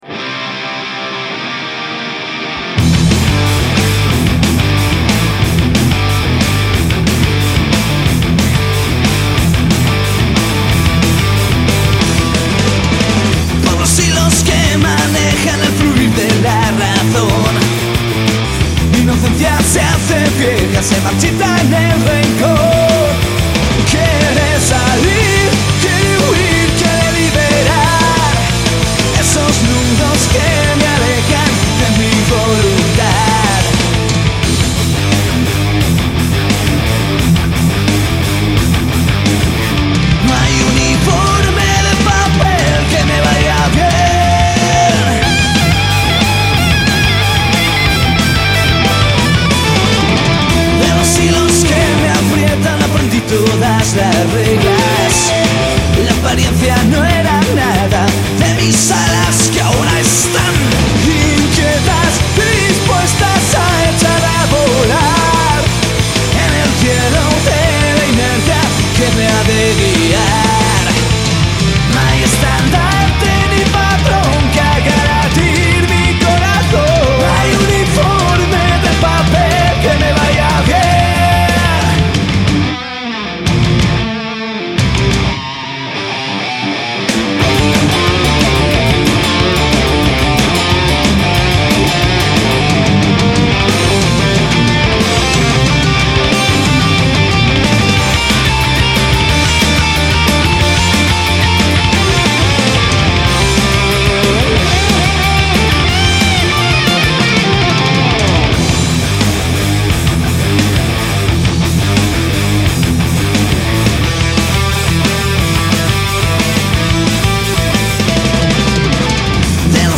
Voz
guitarra rítmica
Batería
Bajo
Guitarra solista